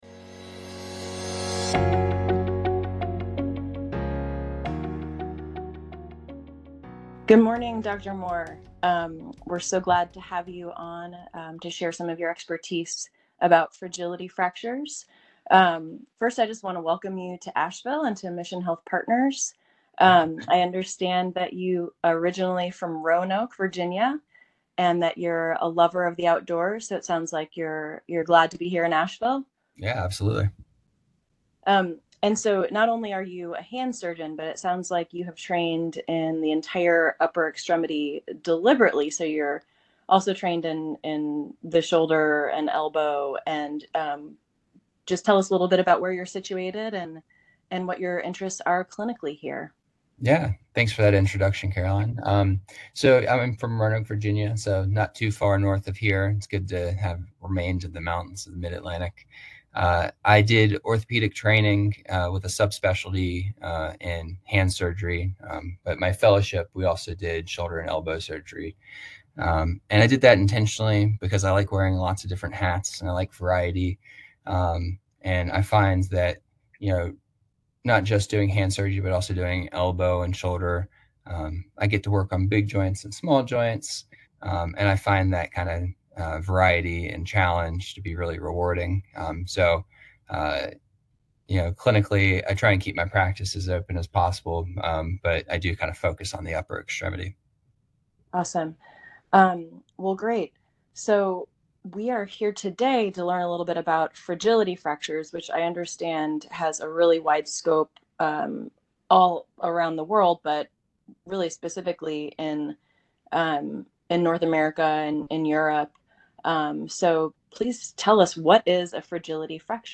Fragility Fractures: A Discussion